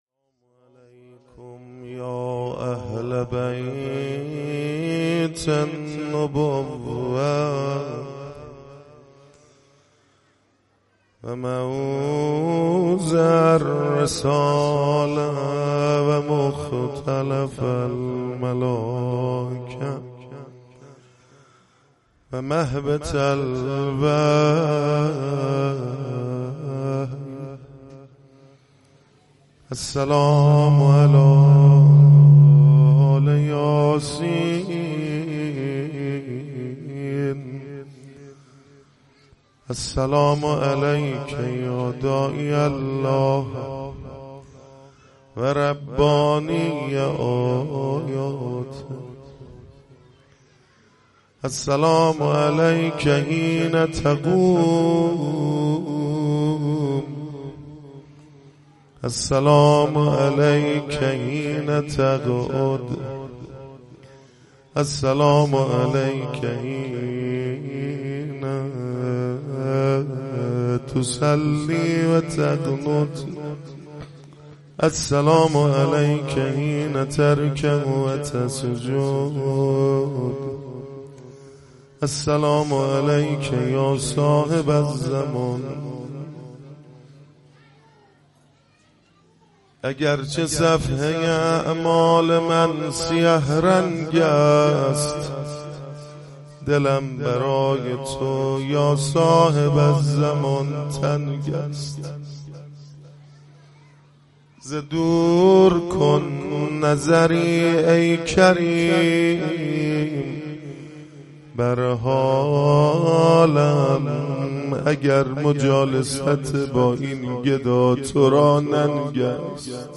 مداحی شب هفتم محرم 98 ( روضه )
03-روضه7.mp3